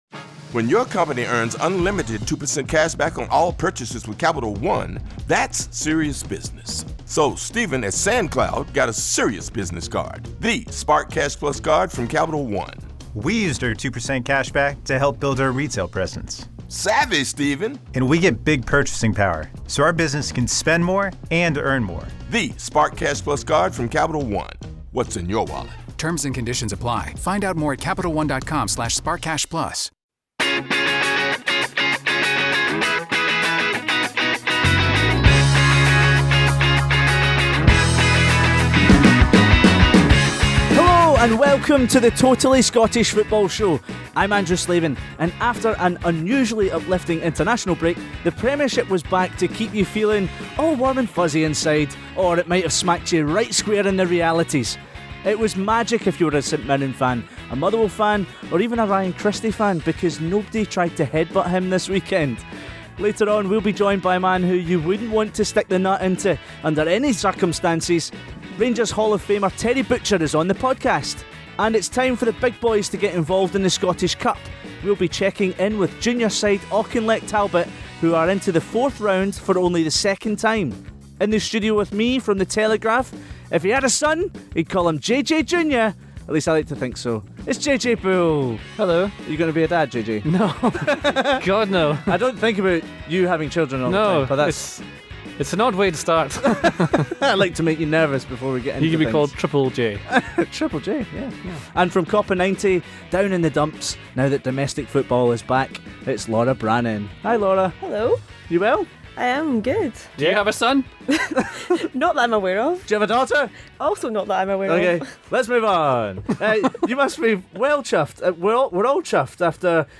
PART 5: Rangers 3-0 Livingston & Terry Butcher interview (26m 00s)